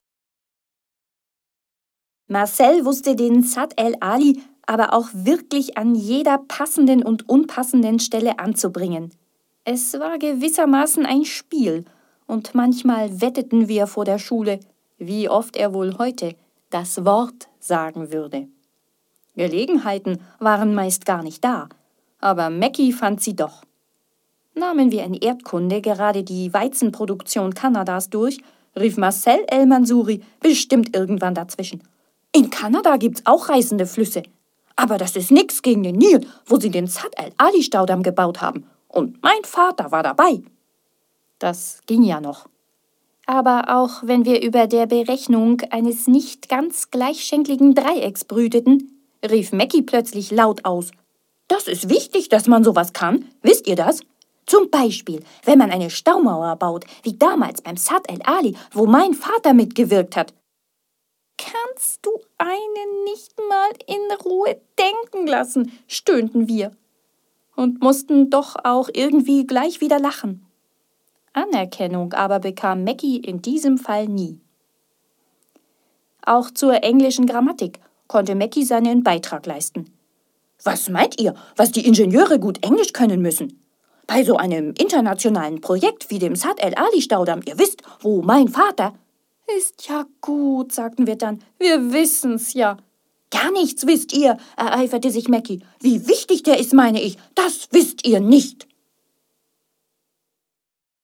Hier sind einige Beispiele, wie es klingt, wenn ich aus meinen Texten etwas vorlese.